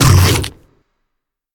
biter-roar-big-4.ogg